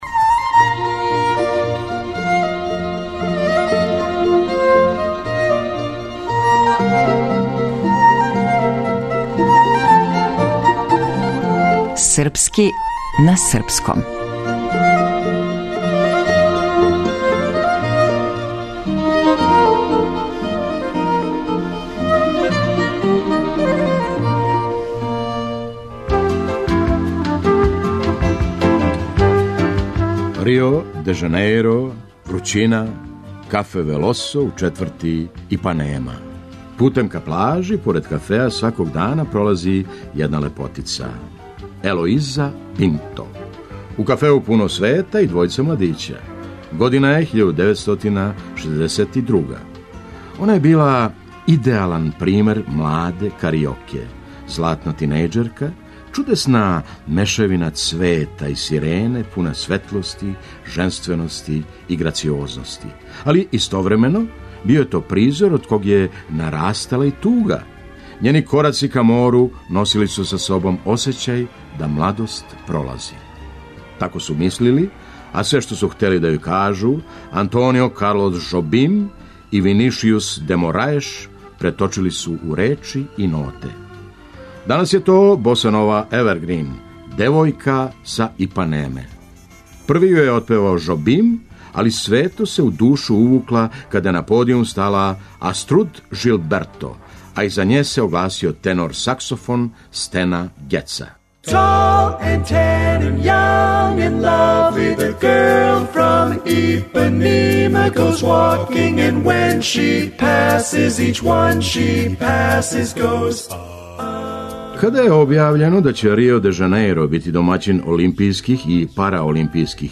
Драмски уметник Феђа Стојановић.